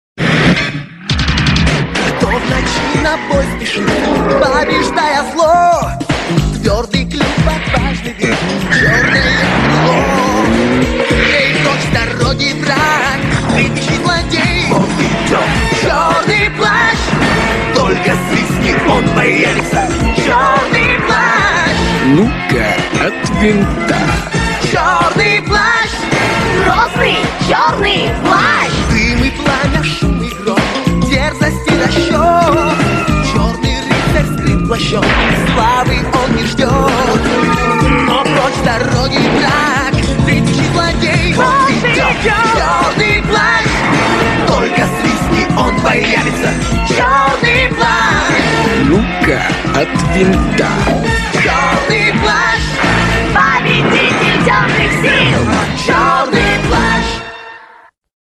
• Качество: 320, Stereo
позитивные
веселые
детские
из мультсериала